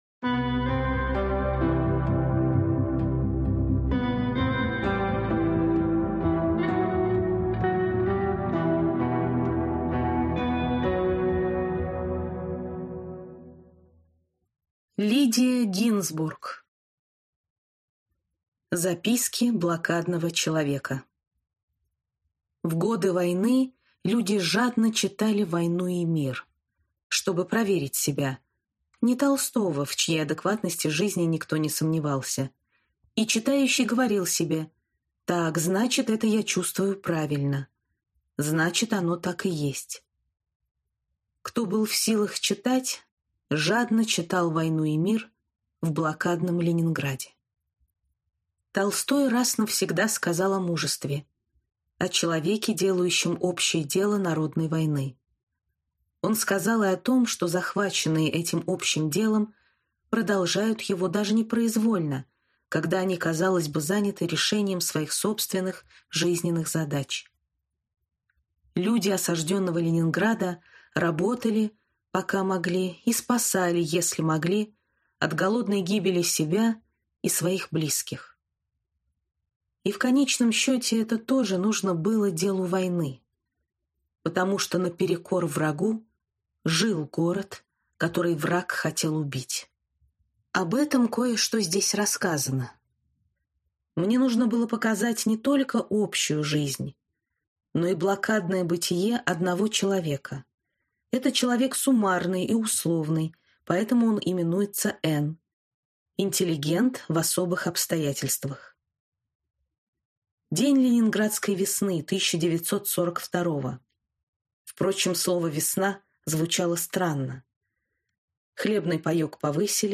Аудиокнига Записки блокадного человека | Библиотека аудиокниг